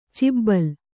♪ cibbal